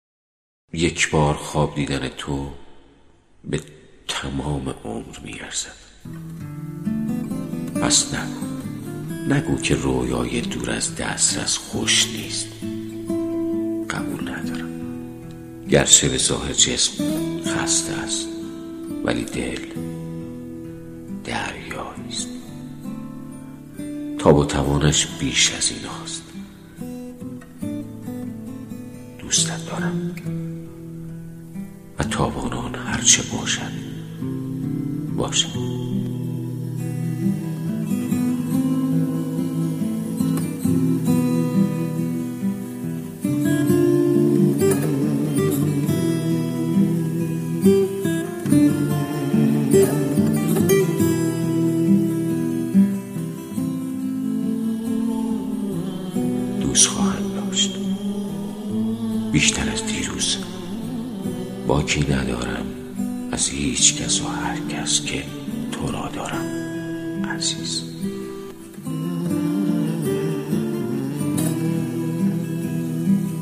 نواهنگی شنیدنی با صدای پرویز پرستویی